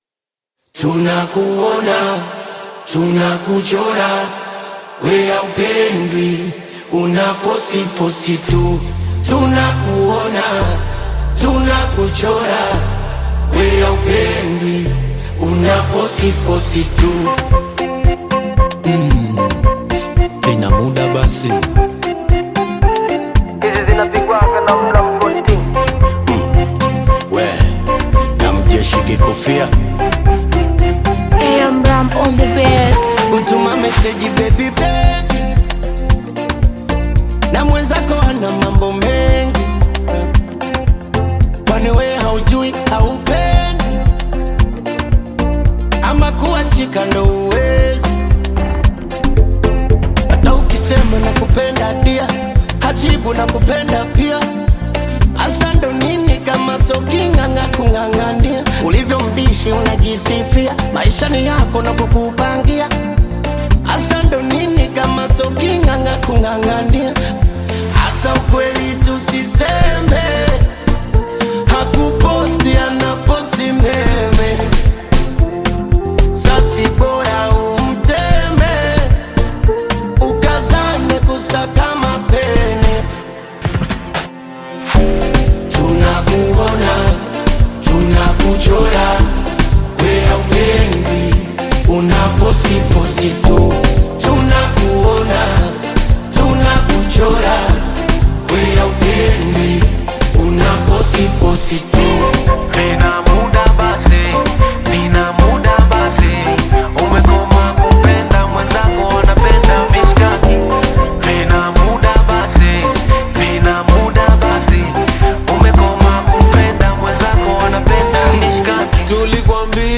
Bongo Flava
Bongo Flava You may also like